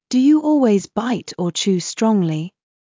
ﾄﾞｩ ﾕｰ ｵｰﾙｳｪｲｽﾞ ﾊﾞｲﾄ ｵｱ ﾁｭｰ ｽﾄﾛﾝｸﾞﾘｰ